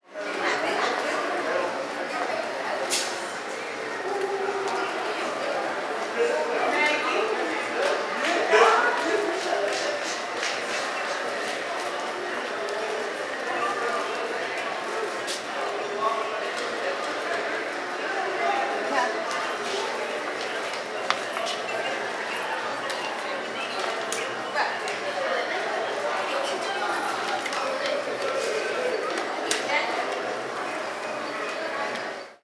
Acuario | SÓNEC | Sonoteca de Música Experimental y Arte Sonoro
Sonido del agua dentro de un acuario
Descargar sonido 00:00 Título: Acuario Editor: Sónec Fecha: 2023 Formato de Archivo: Audio y mp3 Nota de contenido: Sonido del agua dentro de un acuario Nota de idioma: No disponible Materia: Ambientes